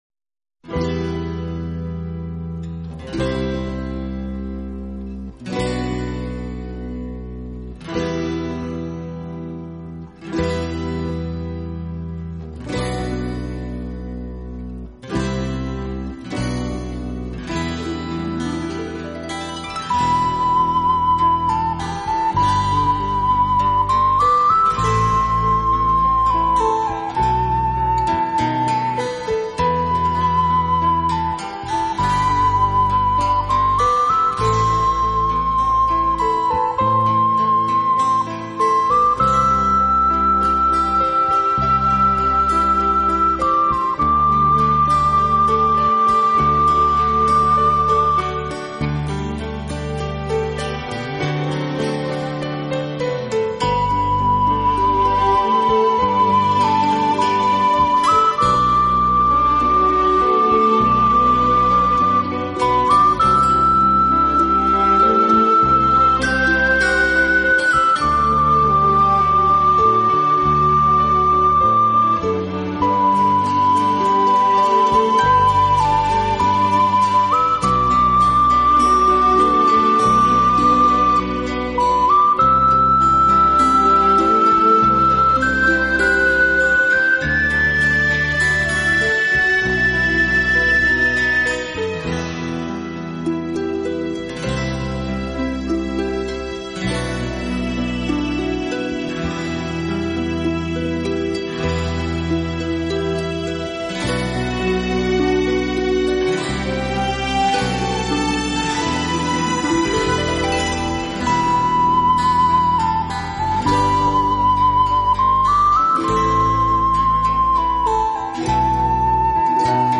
音樂類型： 陶笛音樂